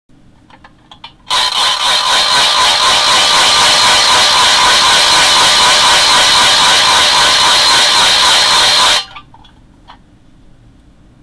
Halloween noisemakers